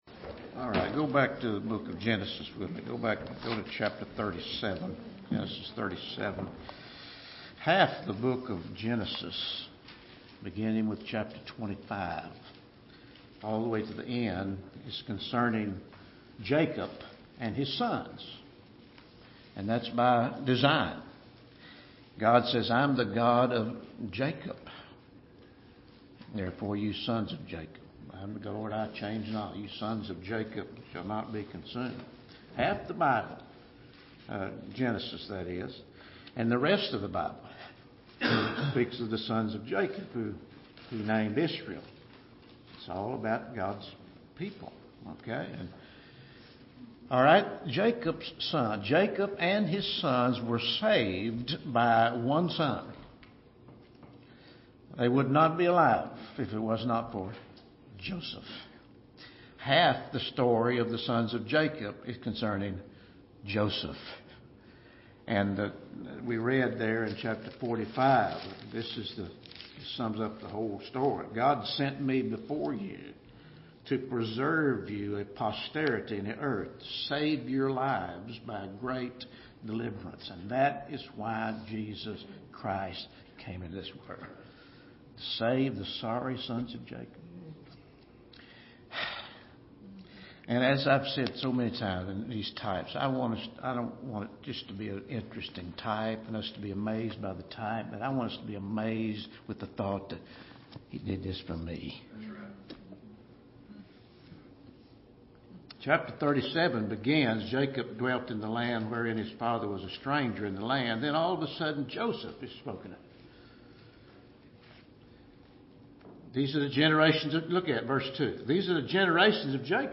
Christ, The Beloved Son | SermonAudio Broadcaster is Live View the Live Stream Share this sermon Disabled by adblocker Copy URL Copied!